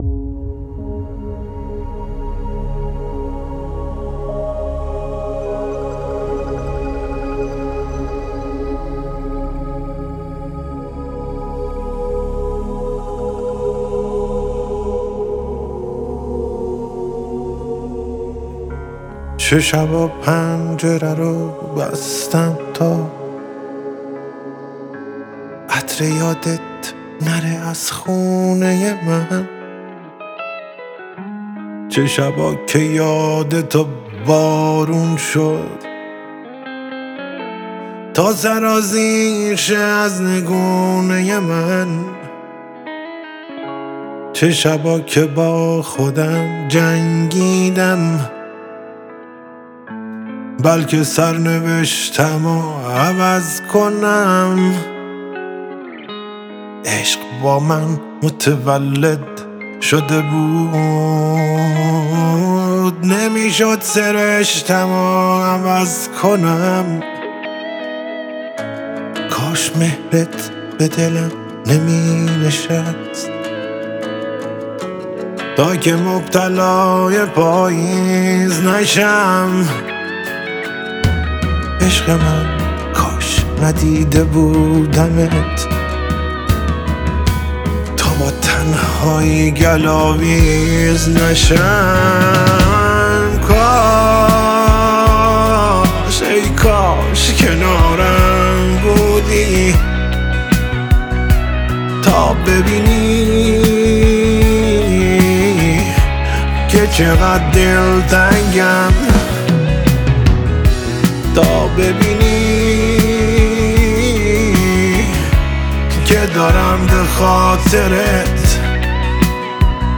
این آهنگ احساسی